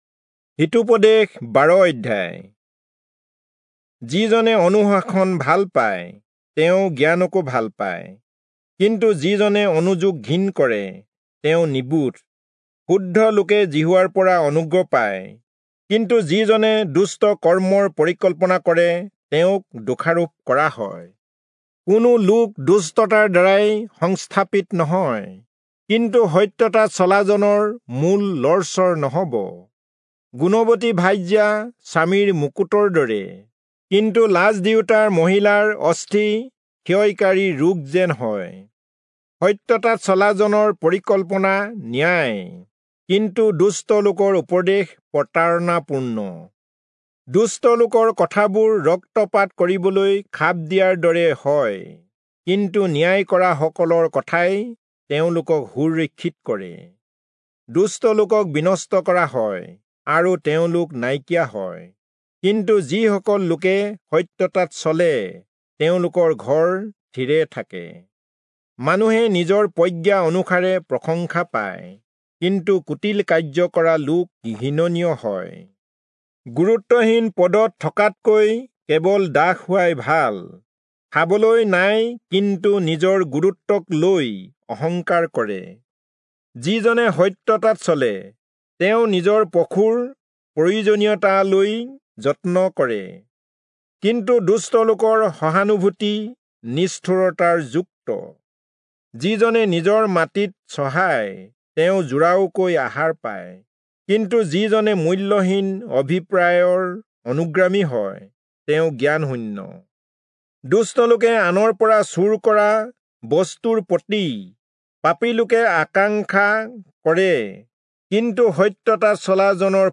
Assamese Audio Bible - Proverbs 14 in Pav bible version